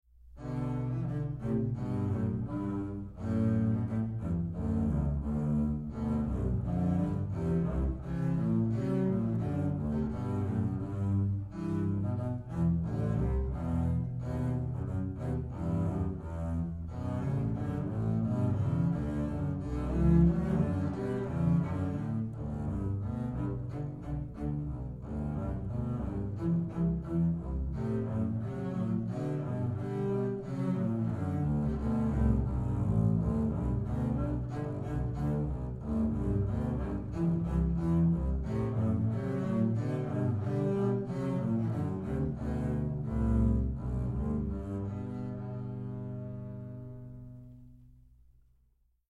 Voicing: String Bass Duet